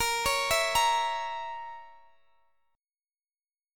Bbdim Chord
Listen to Bbdim strummed